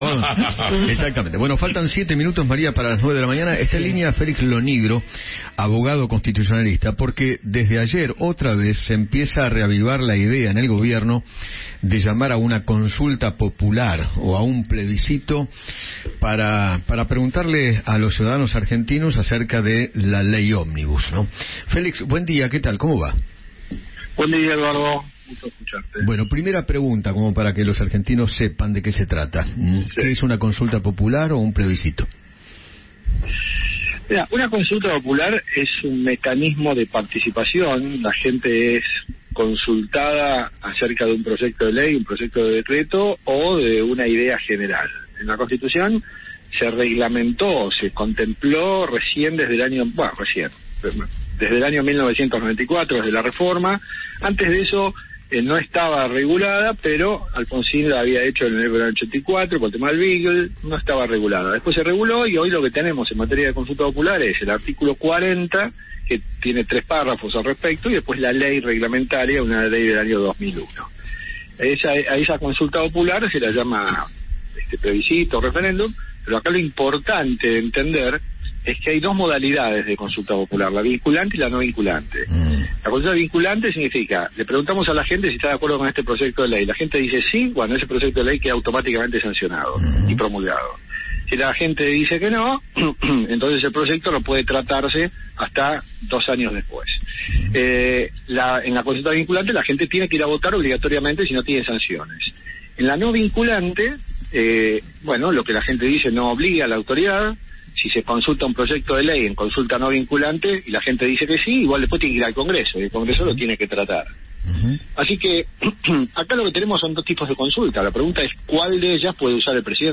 conversó con Eduardo Feinmann sobre la posibilidad de que el Gobierno llame a una consulta popular para preguntarle a los ciudadanos argentinos acerca de la Ley Ómnibus.